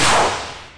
se_explode.wav